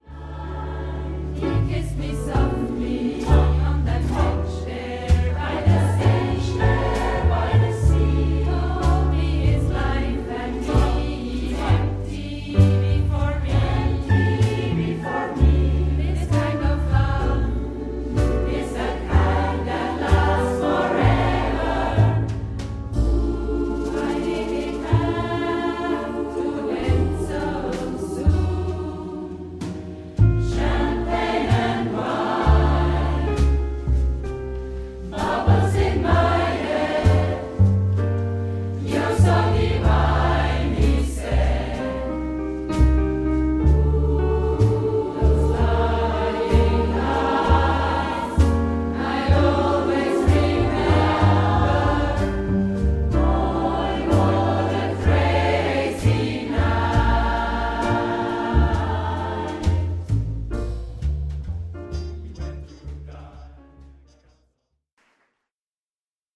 Band
SMATB